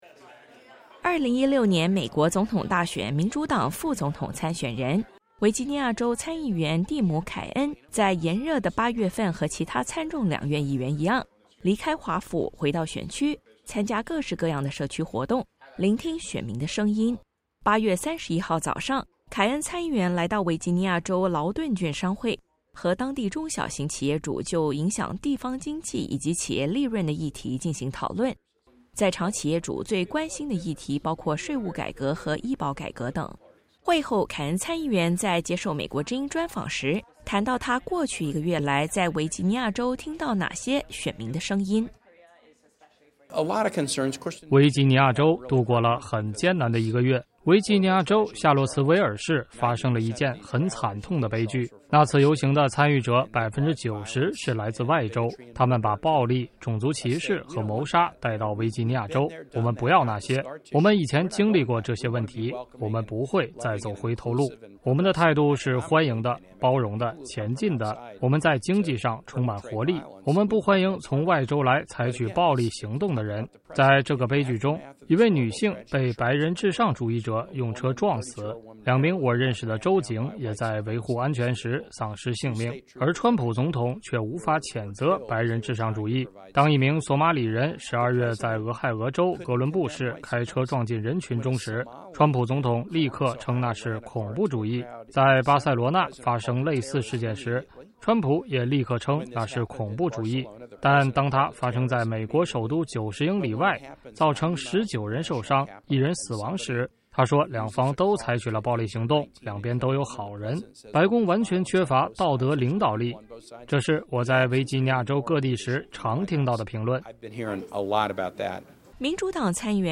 专访前民主党副总统参选人凯恩谈朝核：军事选项必须在台面上
维吉尼亚州利斯堡 —